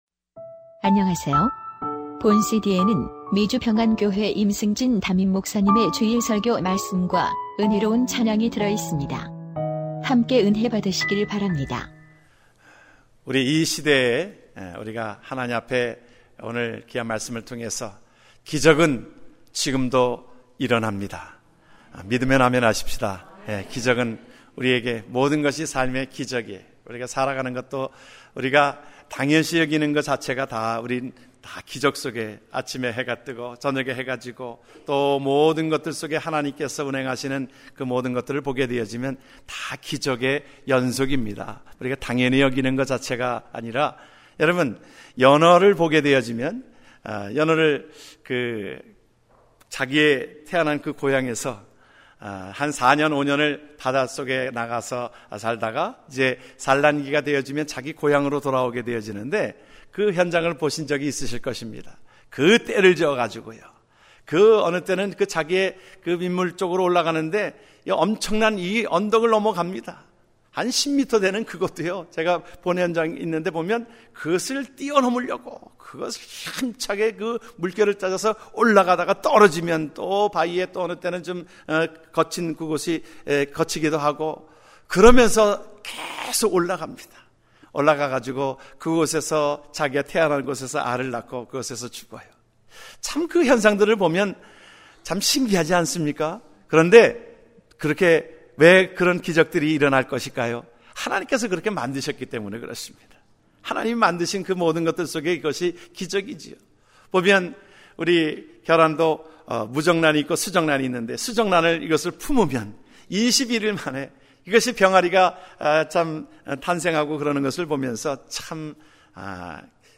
2015년 5월 31일 미주평안교회 주일설교말씀: 기적은 지금도 일어납니다 (요한복음 6:5-13절)